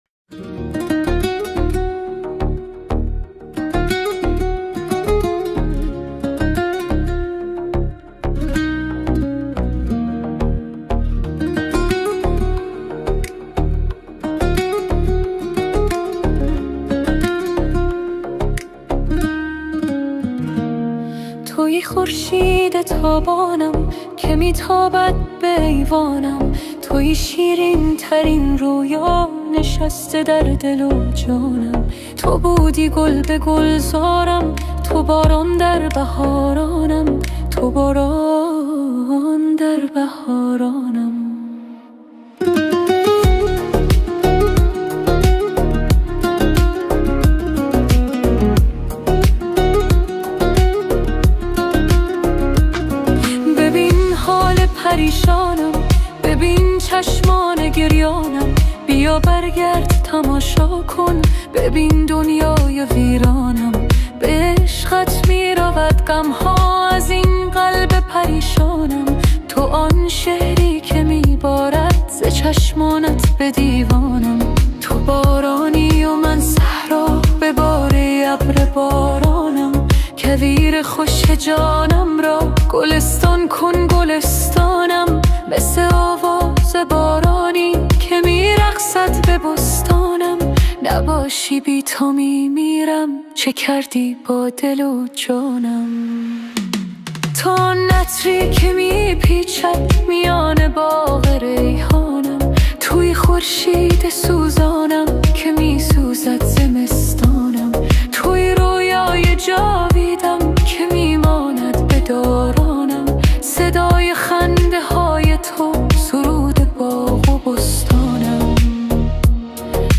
با ملودی جذاب